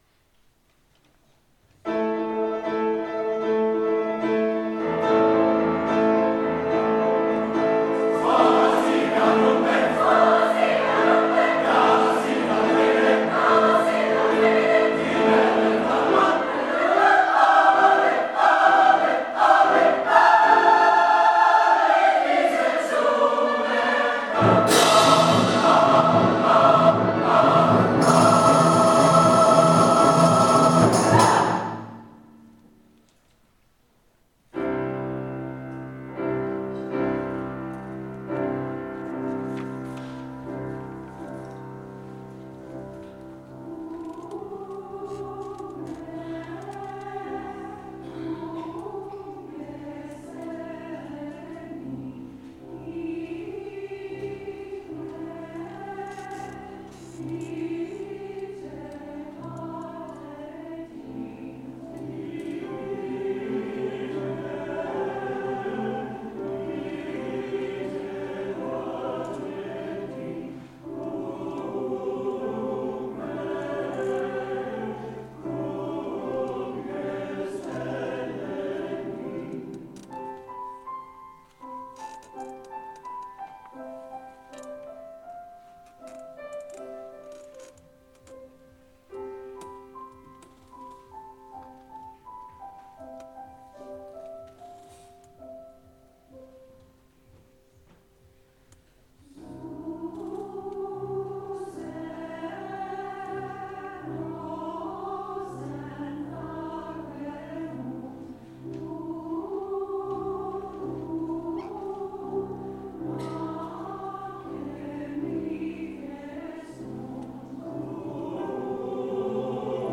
our May 4th concert
Reie – Waz hie gat umbe (four small pieces)    Coro